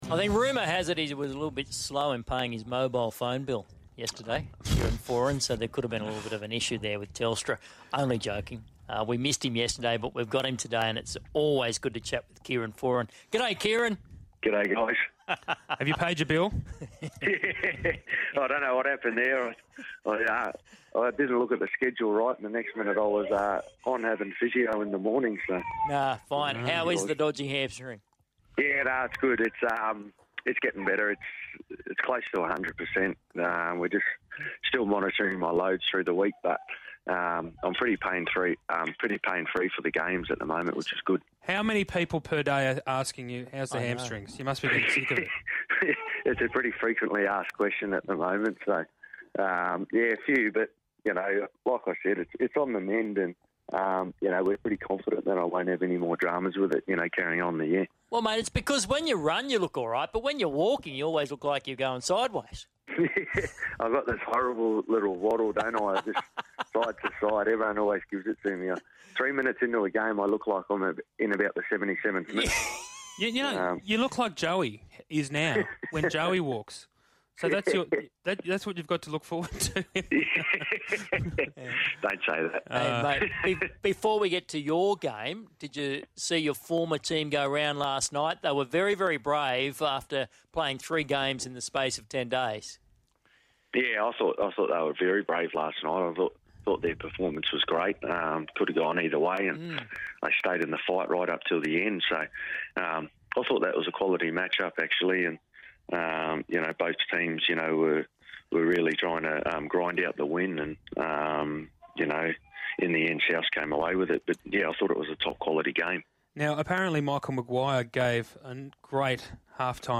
So listening to Foran on the Big Sports Breakfast this morning one thing became clear. He is VERY aware of us not being good enough in attack and that it falls on him and Norman.